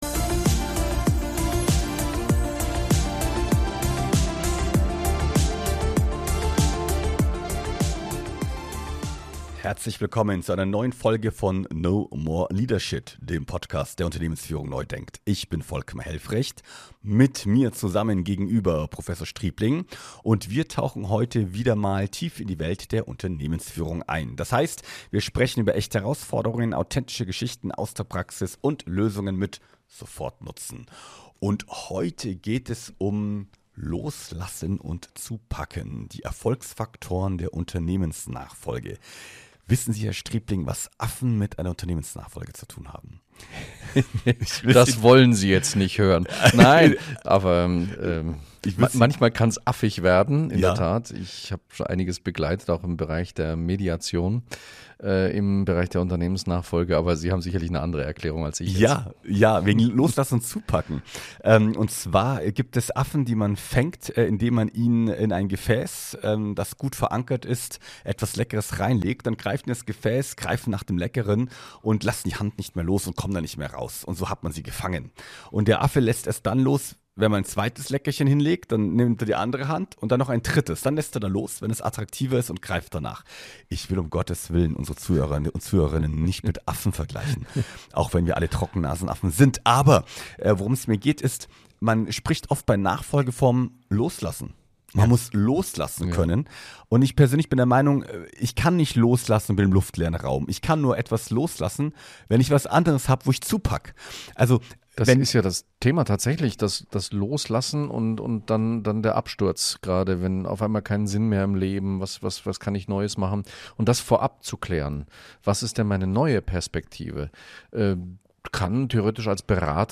Die Hosts sprechen darüber, wie man auch innerhalb der Familie klare Entscheidungen trifft, offen kommuniziert und sich Zeit nimmt, um Sicherheit in den Prozess zu bringen.